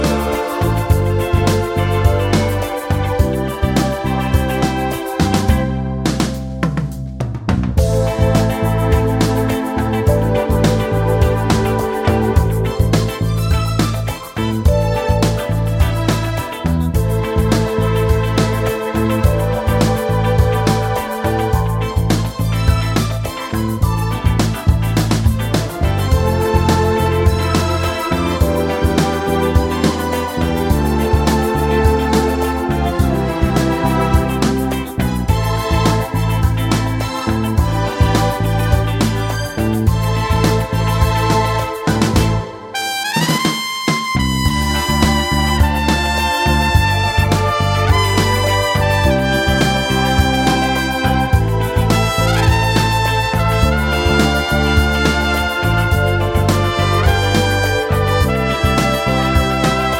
no Backing Vocals Soul / Motown 3:45 Buy £1.50